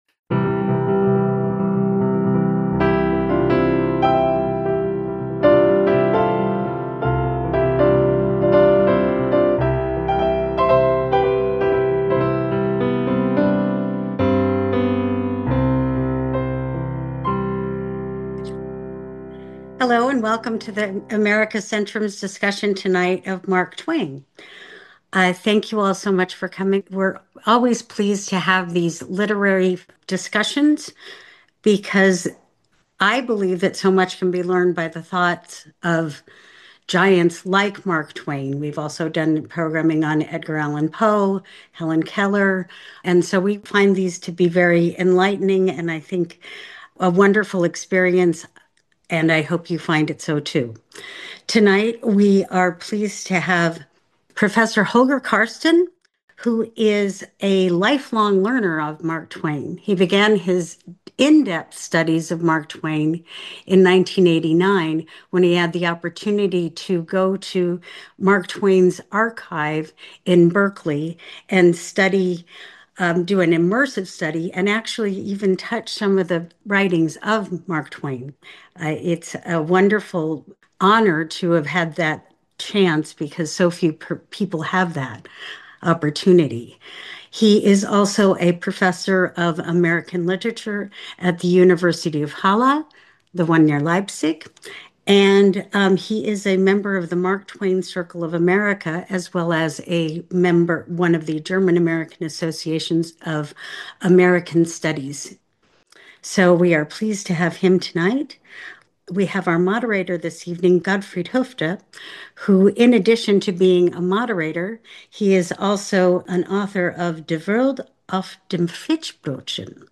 ***This episode was recorded live on 19 November 2025.*** This special episode explores the life and work of Mark Twain, one of the most iconic figures in American literature.